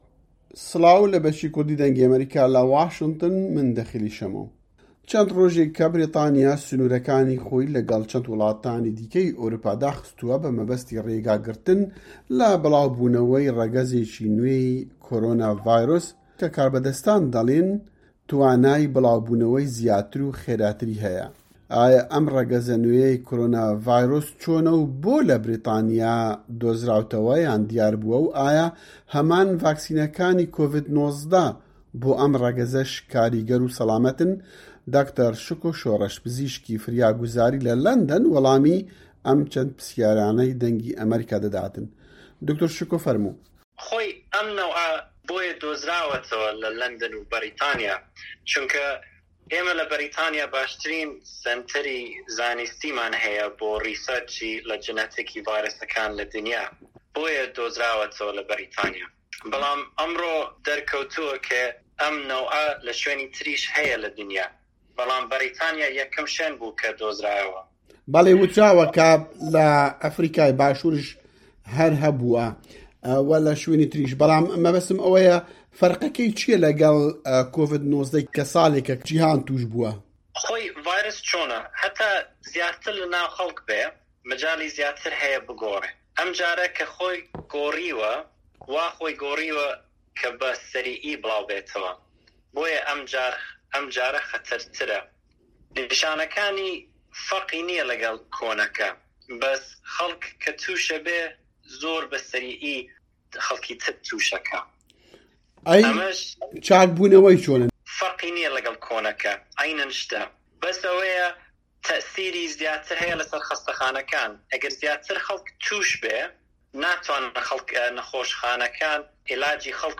جیهان - گفتوگۆکان